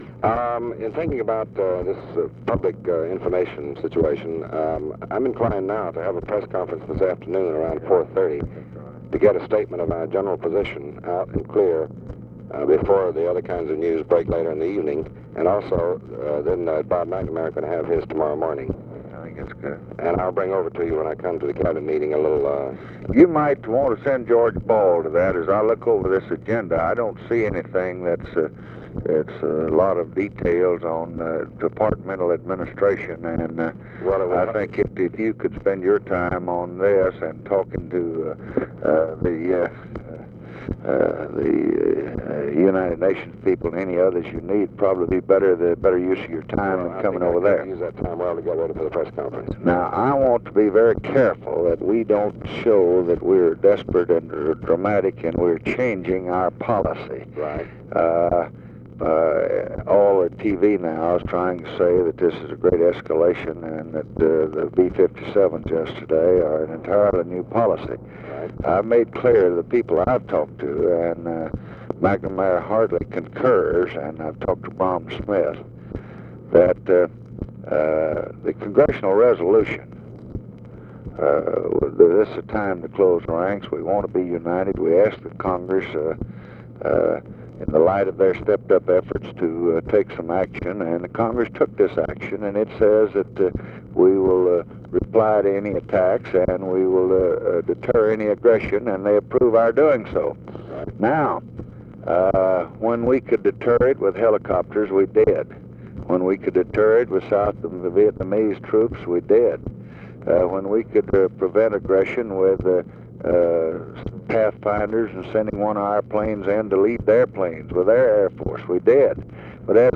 Conversation with DEAN RUSK, February 25, 1965
Secret White House Tapes